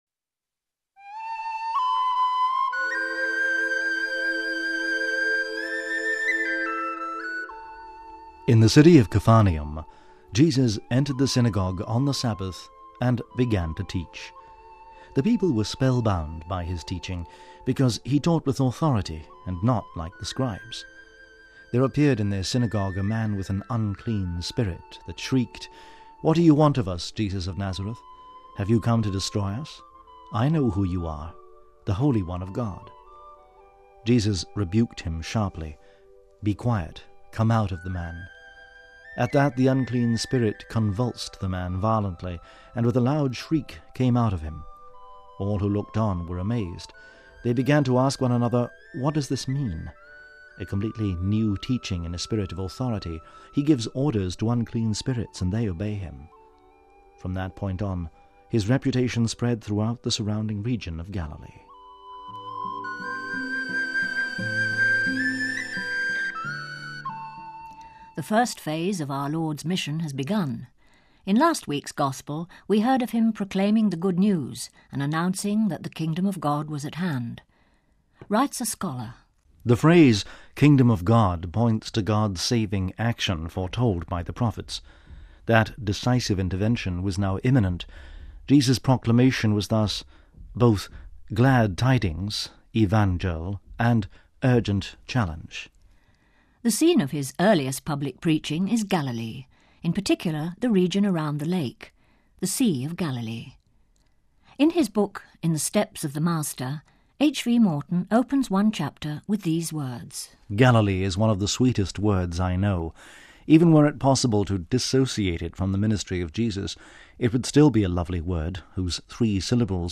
readings and reflections for the Fourth Sunday of the Year